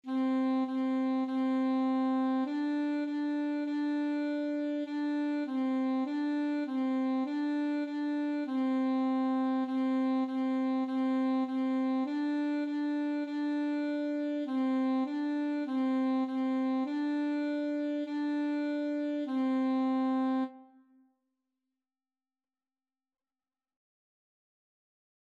Free Sheet music for Alto Saxophone
2/4 (View more 2/4 Music)
C5-D5
Beginners Level: Recommended for Beginners
Classical (View more Classical Saxophone Music)